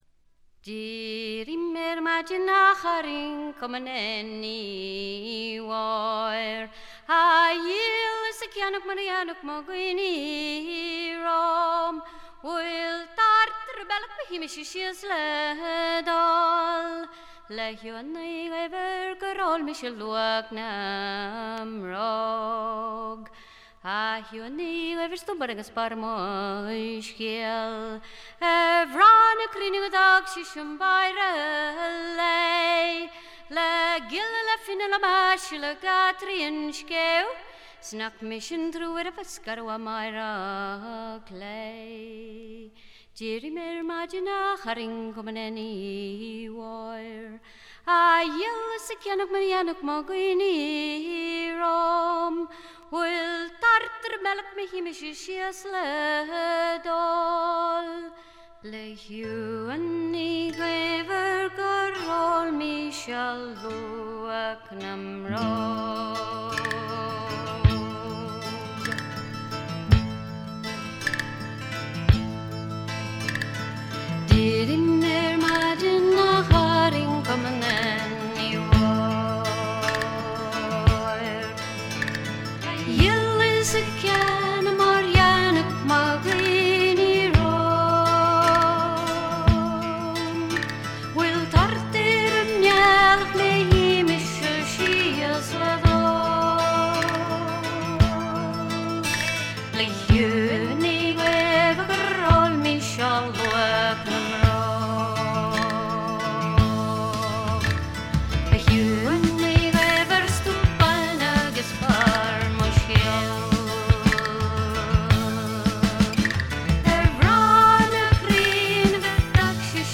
メランコリックな曲が多く彼女のヴォーカルは情感を巧みにコントロールする実に素晴らしいもの。
試聴曲は現品からの取り込み音源です。
Vocals, Harp [Irish]
Recorded & mixed At Hollywood Studios, Rome, April 1983.